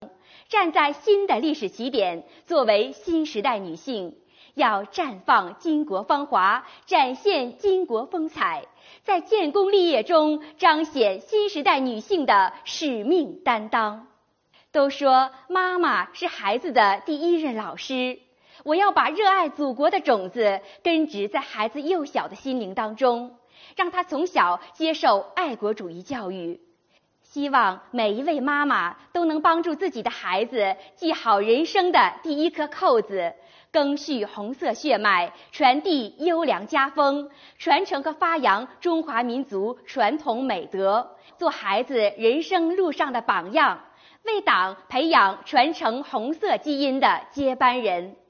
微宣讲共设100期，改变台上一人讲、台下大家听的方式，由身边人讲述自身感悟，引发大家共鸣。